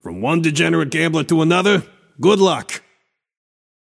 Shopkeeper voice line - From one degenerate gambler to another: Good luck.